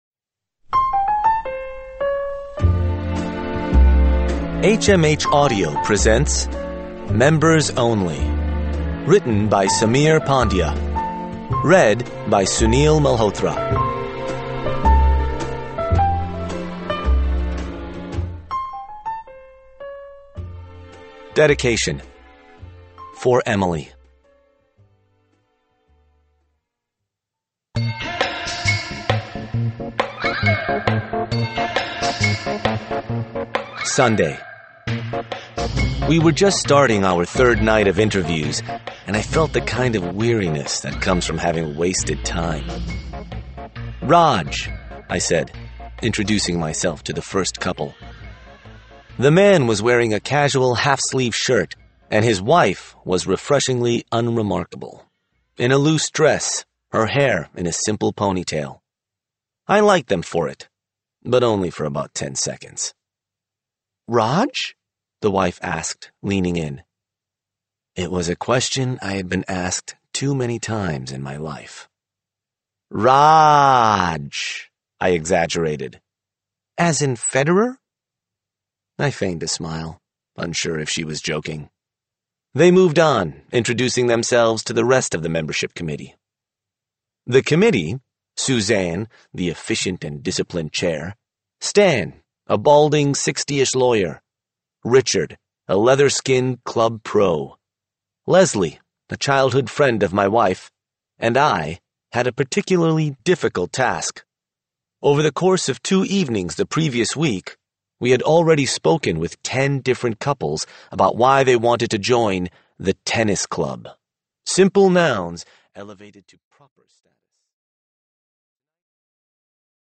Unabridged
Audiobook